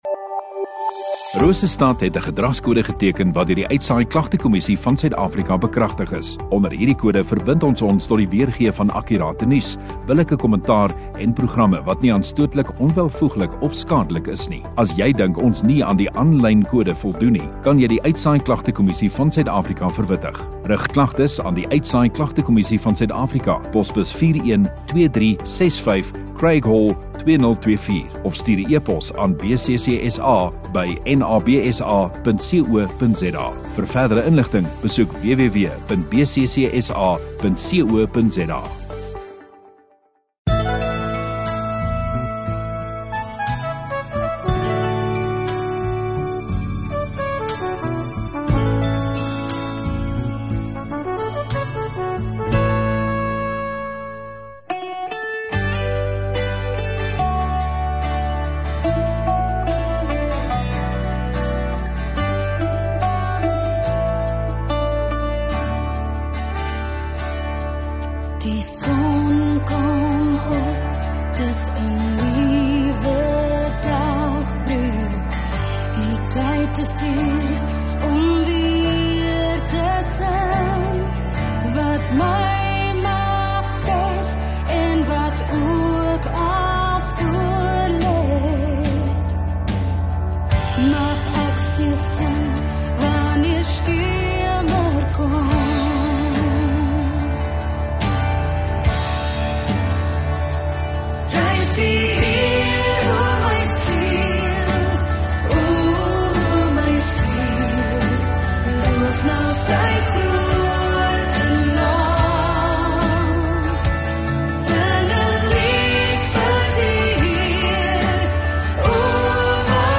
25 Aug Sondagaand Erediens